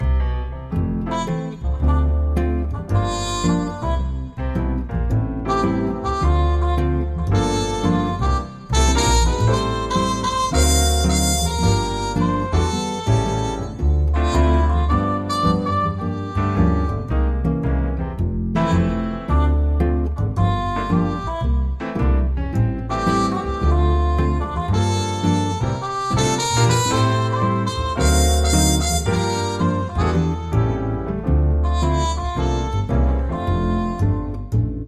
CalmMusic.mp3